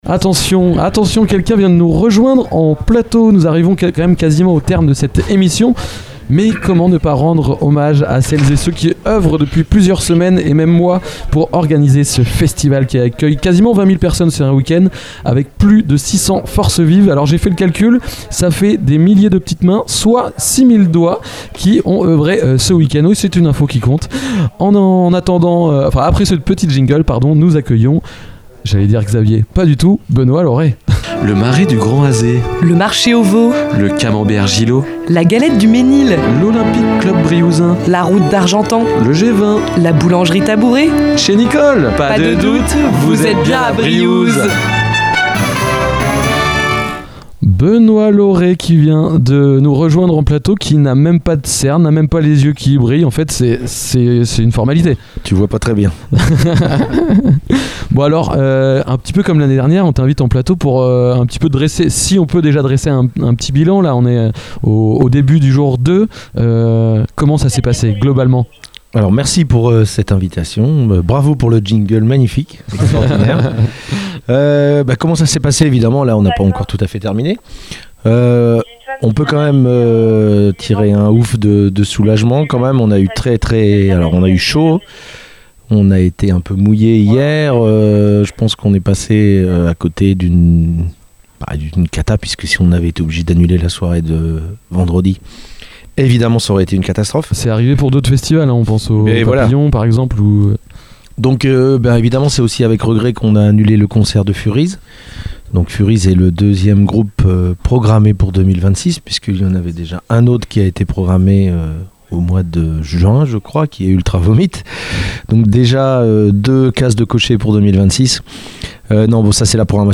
Dans cette interview réalisée dans le cadre de l’émission spéciale enregistrée au Art Sonic à Briouze, les radios de l’Amusicale — Ouest Track, Station B, Radio Pulse, Kollectiv', 666, Radio Sud Manche, Radio Coup de Foudre, RADAR, Radio Phénix, Radio Campus Rouen et TST Radio — sont allées à la rencontre de celles et ceux qui font vivre le festival.